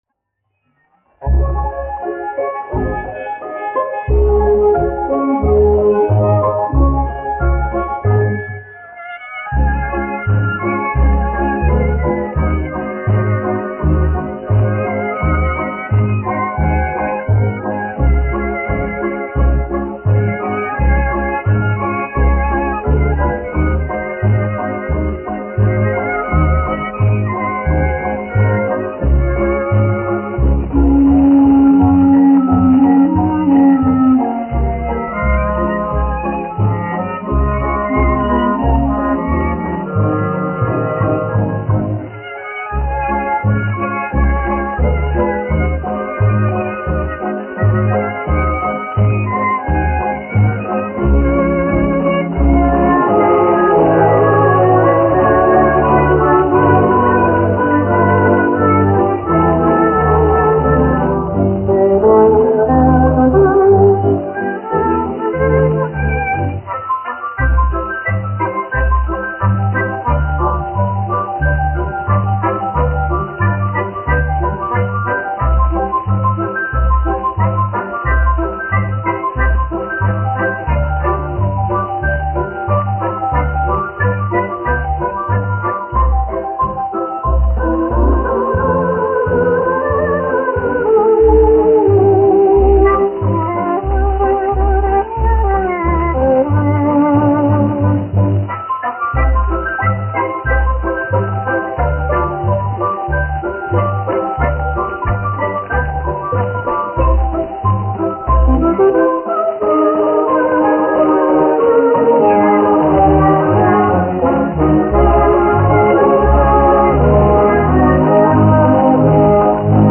1 skpl. : analogs, 78 apgr/min, mono ; 25 cm
Populārā instrumentālā mūzika
Fokstroti
Skaņuplate